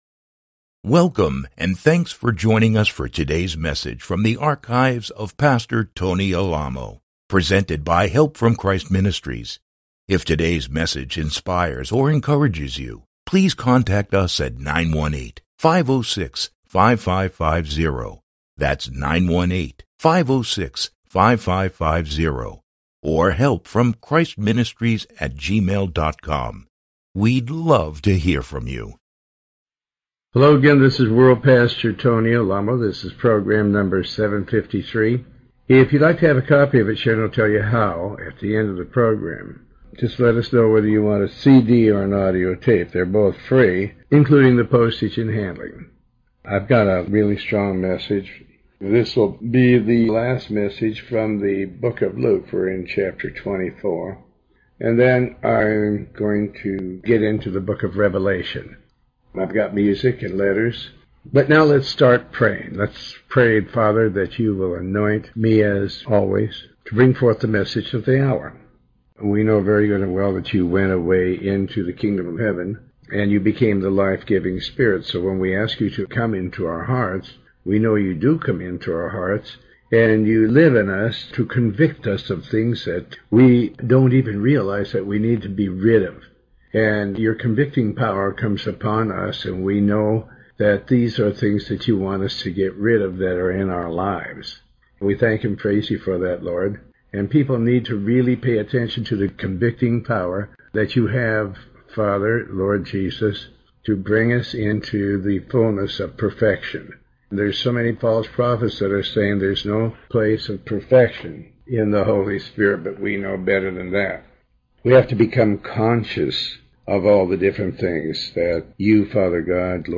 with World Pastor Tony Alamo - Stream Revelation programs #753 Part 2